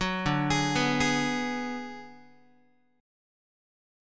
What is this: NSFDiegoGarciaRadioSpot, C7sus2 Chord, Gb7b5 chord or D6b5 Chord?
D6b5 Chord